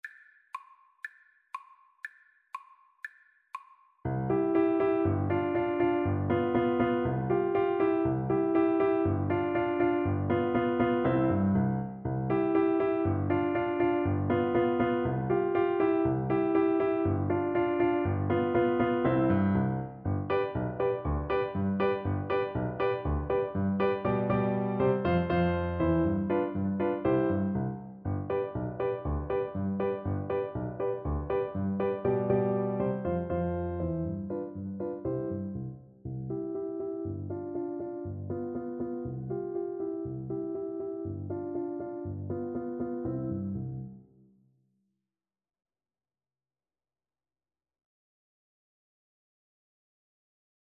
Allegro (View more music marked Allegro)
2/4 (View more 2/4 Music)
Classical (View more Classical Oboe Music)